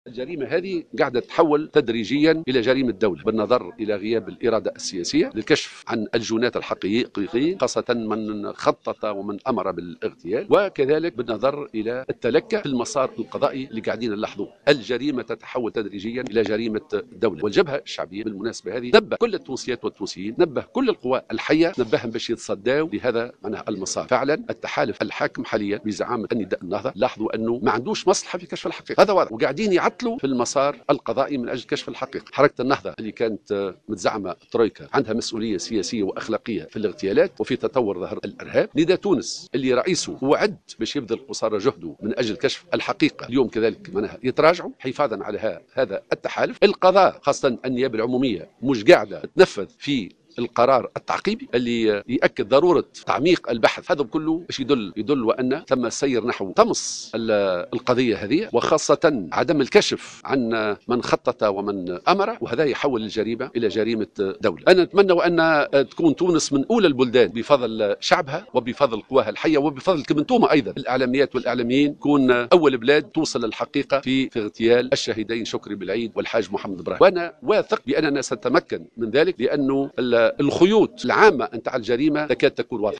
وأضاف في تصريح لـ "الجوهرة اف أم" أن جريمة الاغتيال بصدد التحول إلى جريمة دولة وهناك تعطيل للمسار القضائي للكشف عن الحقيقة، بحسب تعبيره على هامش تظاهرة اليوم للإعلان عن برنامج إحياء الذكرى الثالثة لاغتيال الشهيد شكري بلعيد.